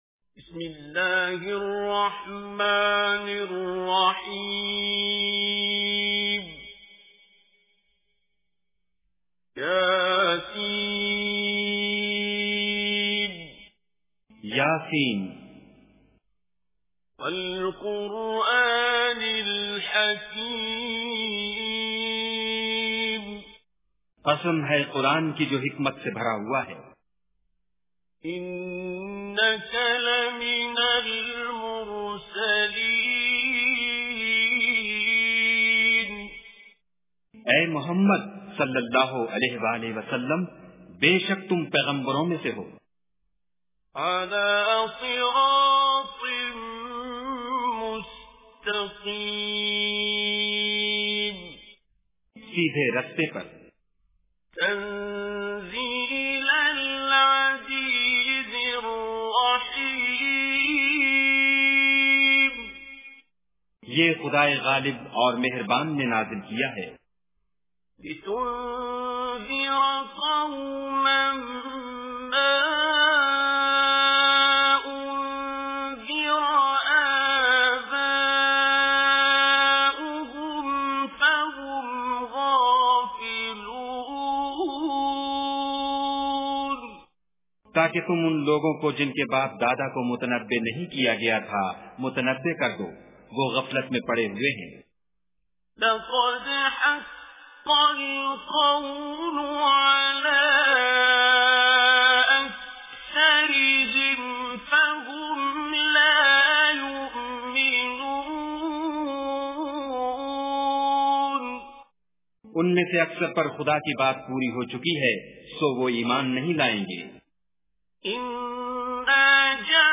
Surah Yaseen Recitation with Urdu Translation
surah-yasin.mp3